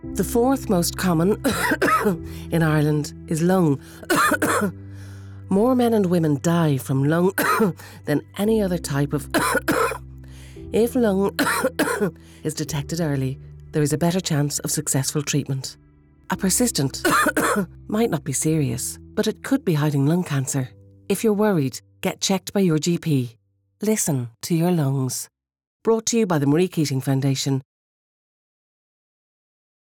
Marie Keating Radio Ad